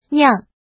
怎么读
niàng
niang4.mp3